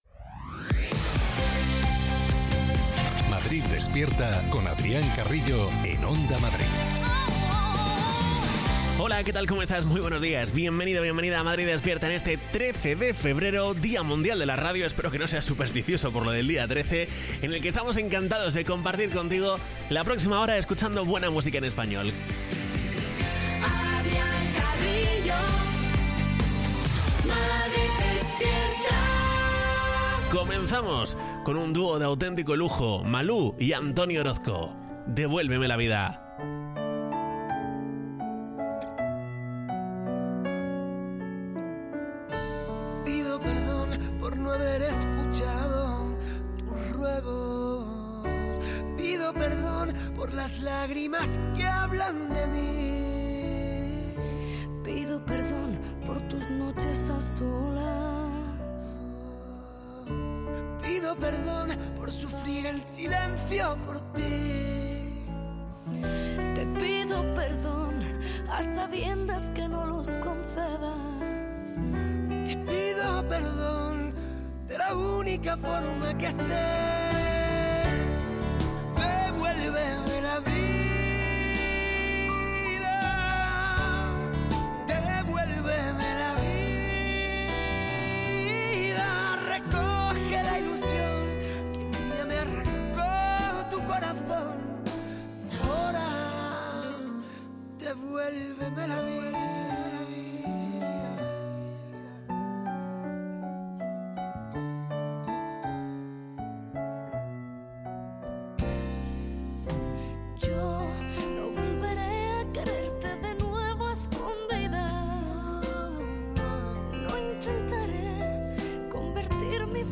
Morning show